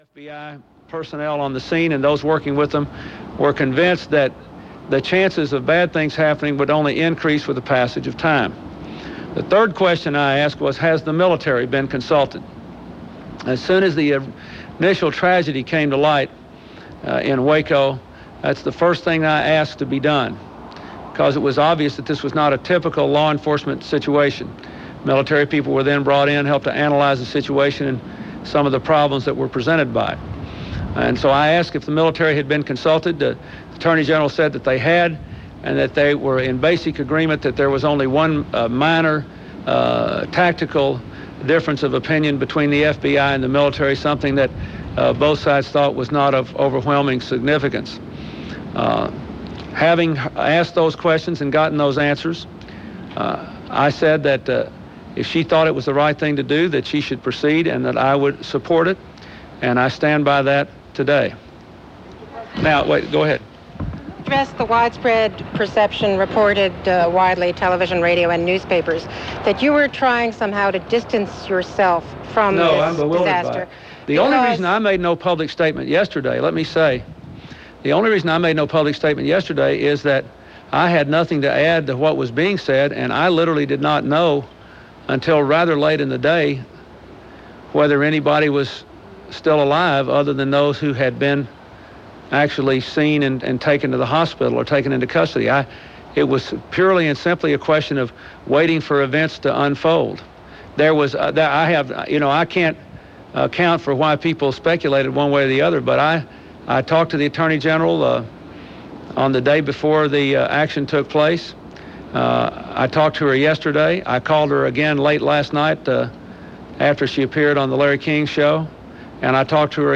April 20, 1993 - A Waco Postmortem - A Bill Clinton Press Conference - A Day In L.A. - News from ABC Radio - KABC, Los Angeles.